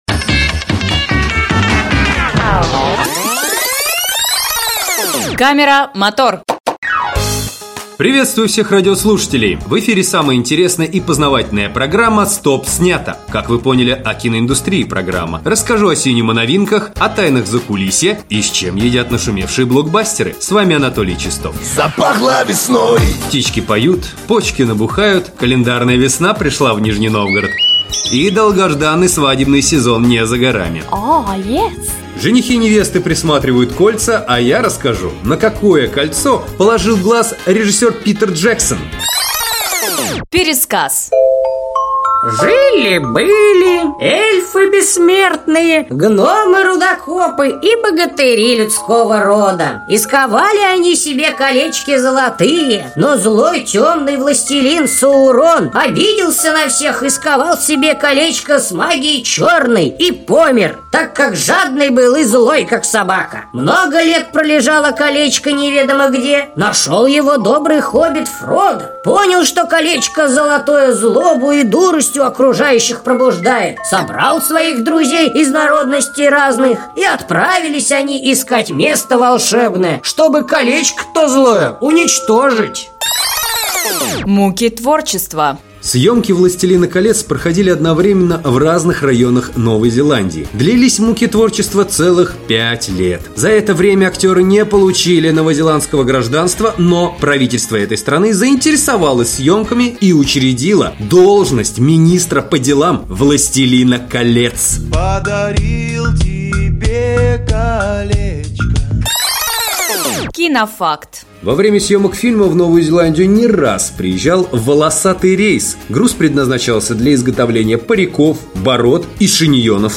Мужской
Баритон